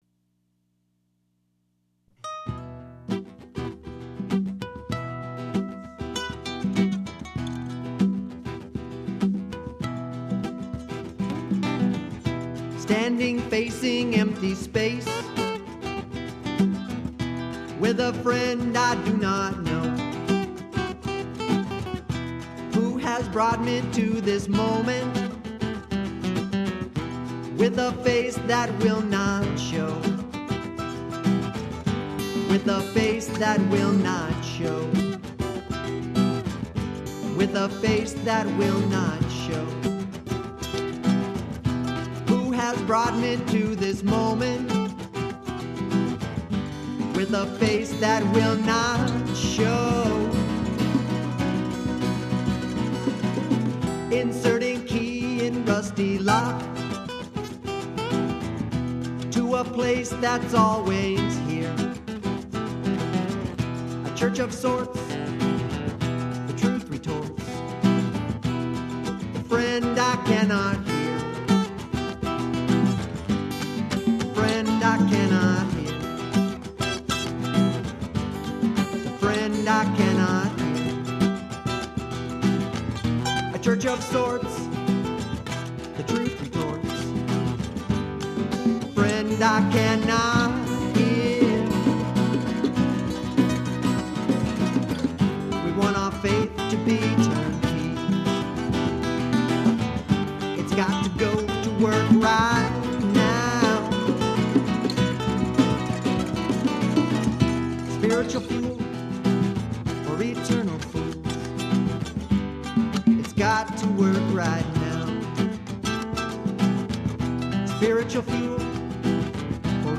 BLUEGRASS FOLK JAM ROCK